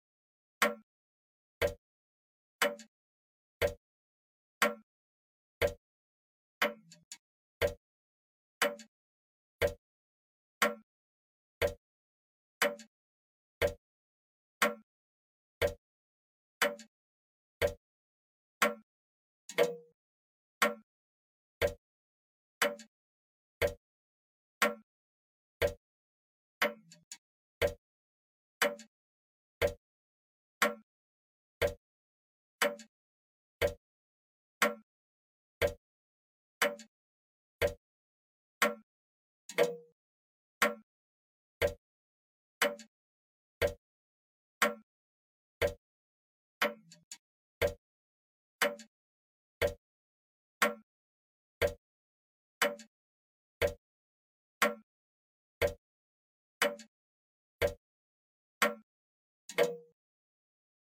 괘종시계
괘종시계의 "똑딱" 소리